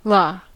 Ääntäminen
IPA : /ˈlɑː/